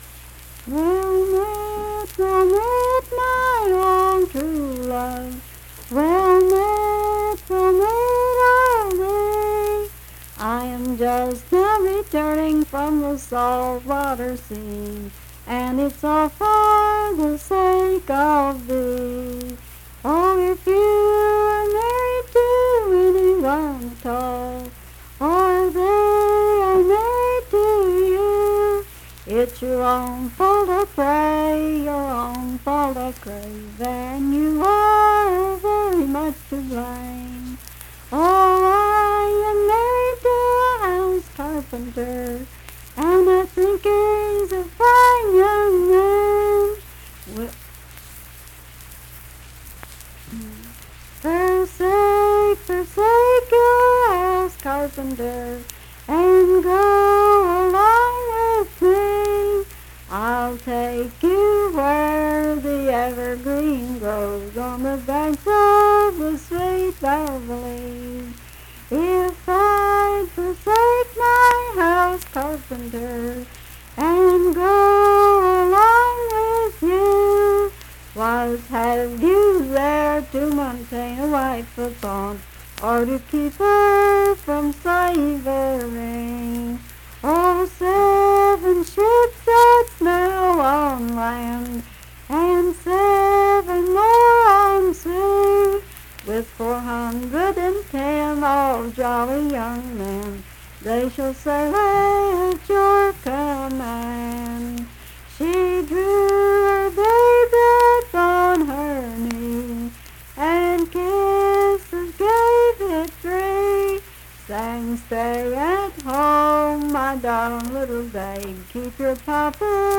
Unaccompanied vocal music
Voice (sung)
Sutton (W. Va.), Braxton County (W. Va.)